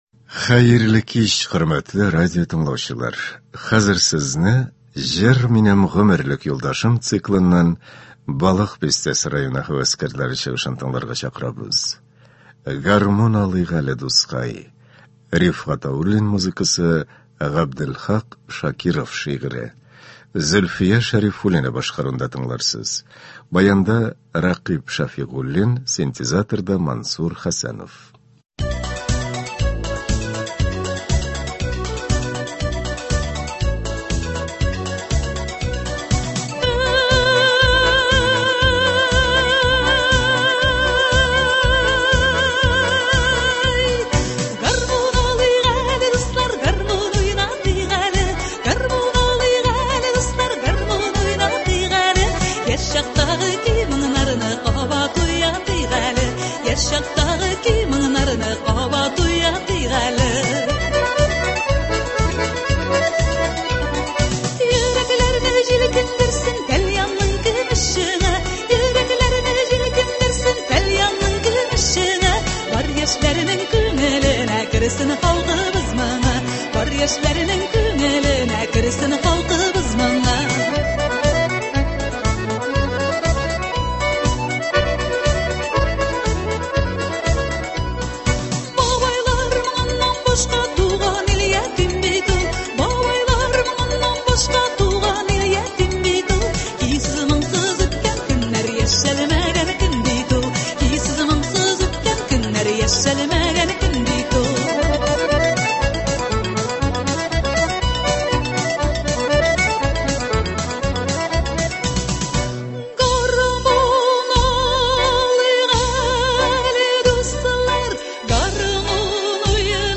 Концерт (06.03.23)